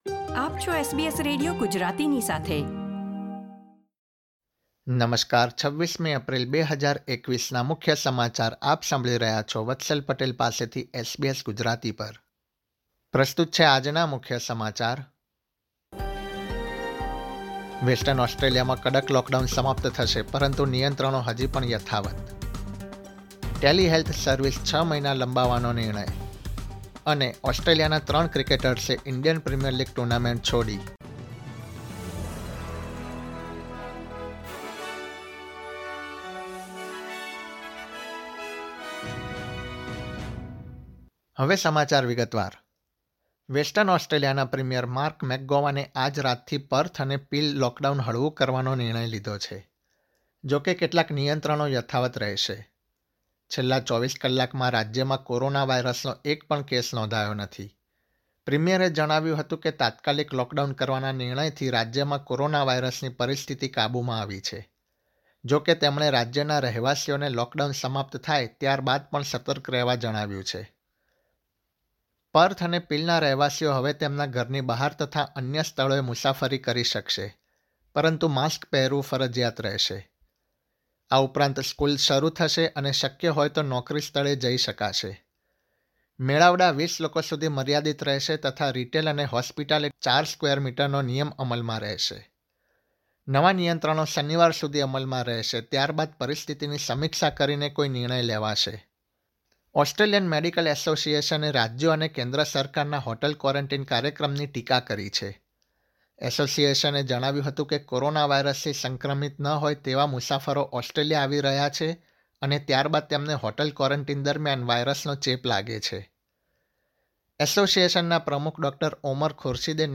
SBS Gujarati News Bulletin 26 April 2021
gujarati_2604_newsbulletin.mp3